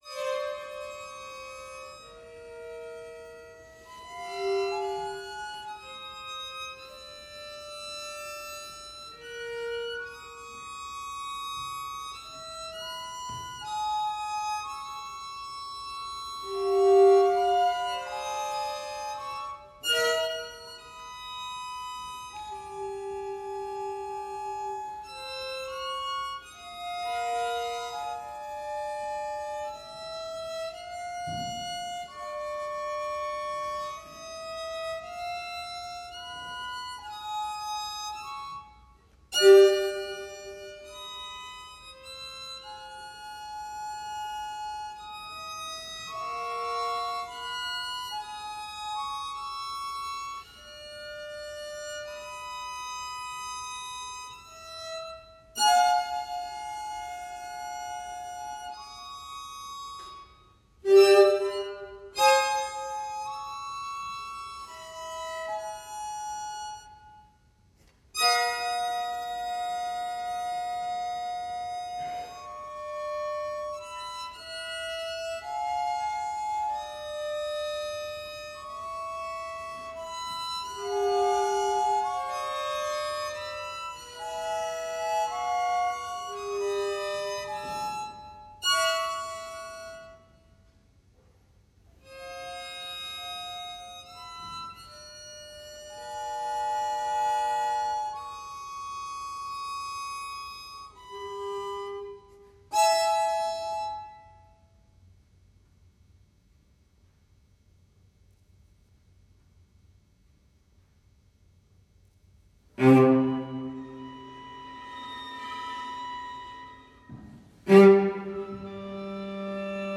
string quartets